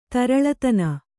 ♪ taraḷatana